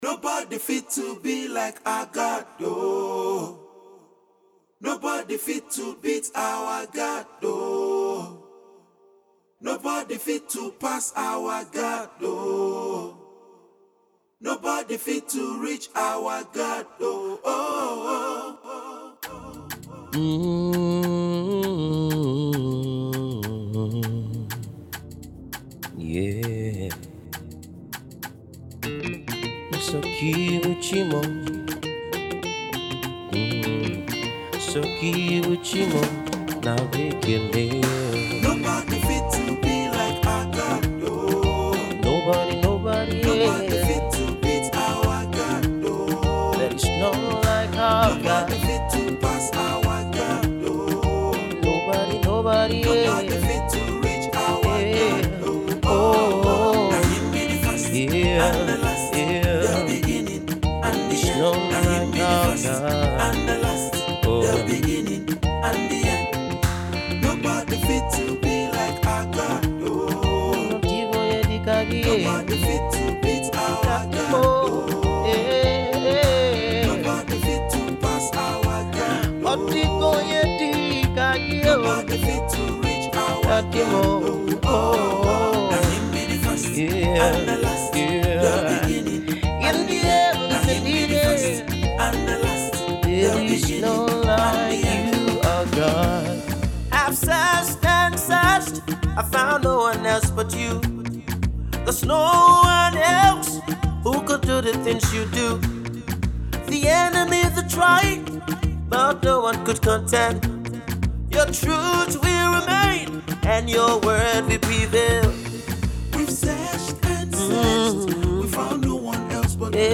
inspirational song